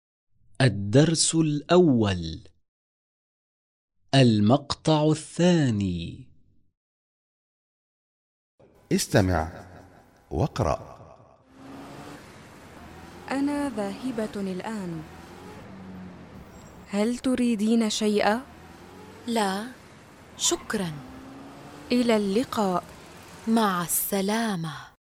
1.2. Diyalog-2